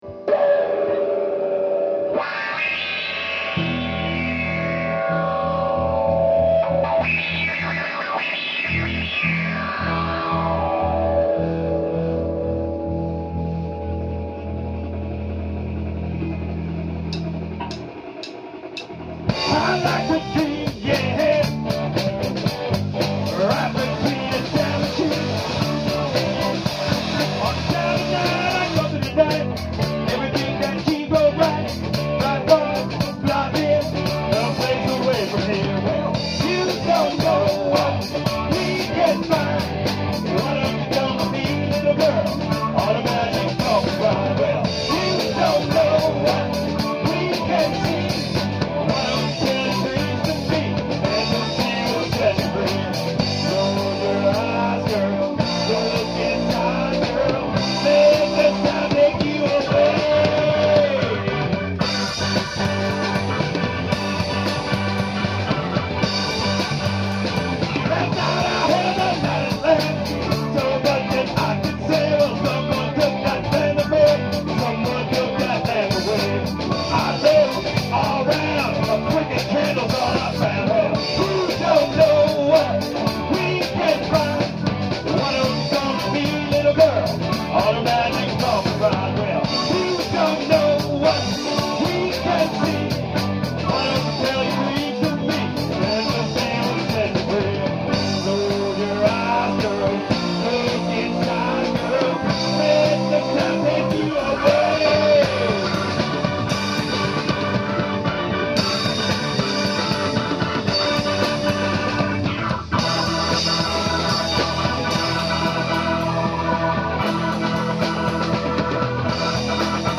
guitar, vocals
keyboards, backing vocals
bass, vocals
drums
sax, flute, tambourine, vocals